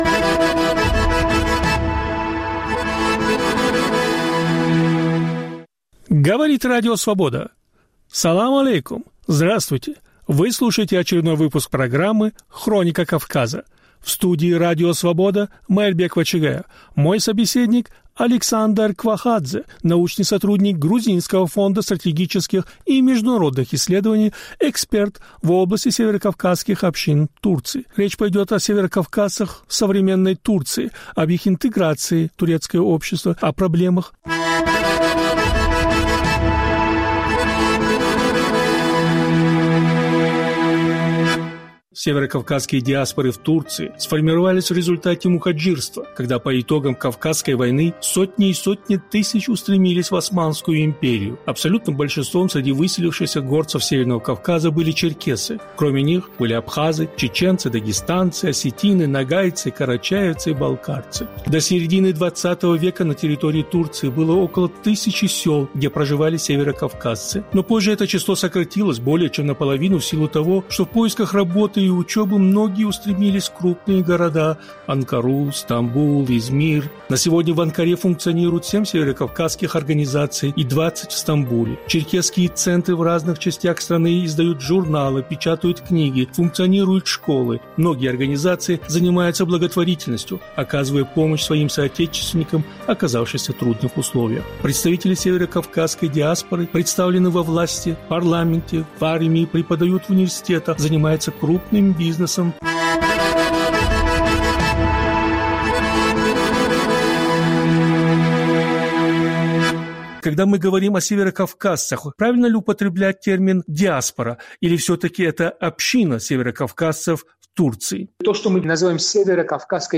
Повтор эфира от 22 августа 2021 года.